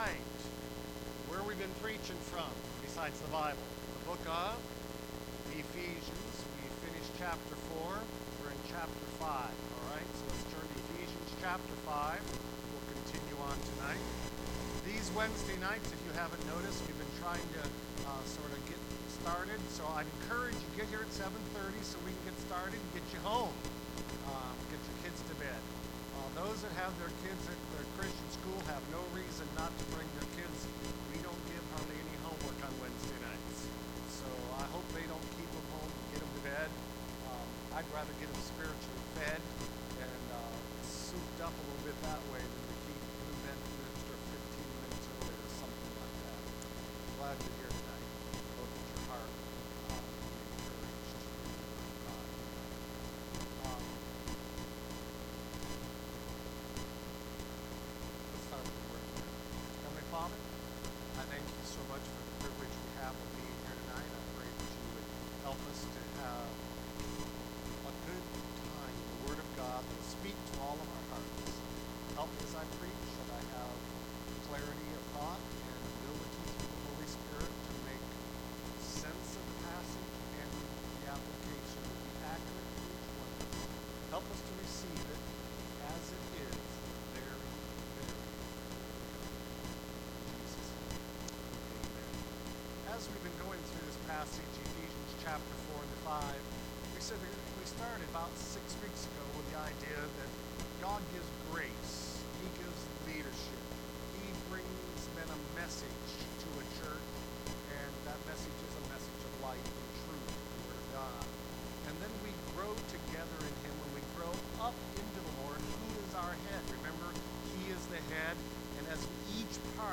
Service Type: Wednesday Prayer Service